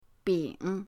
bing3.mp3